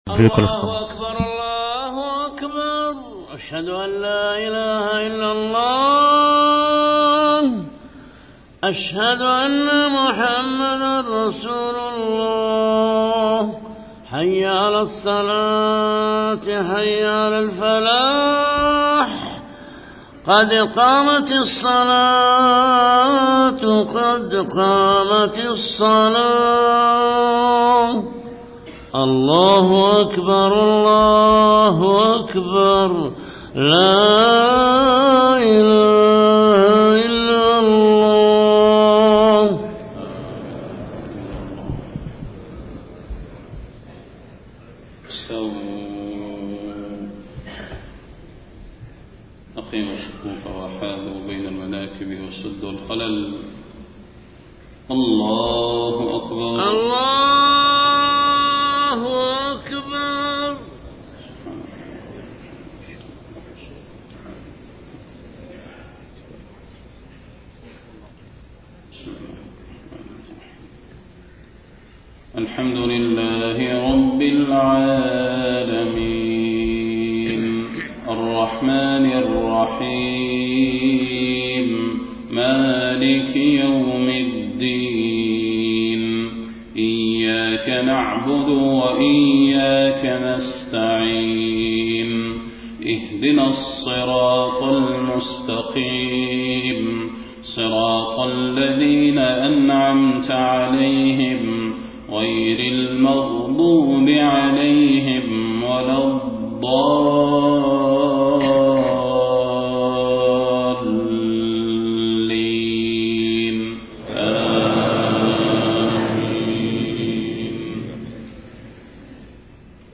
صلاة الفجر 21 صفر 1431هـ سورتي السجدة و الإنسان > 1431 🕌 > الفروض - تلاوات الحرمين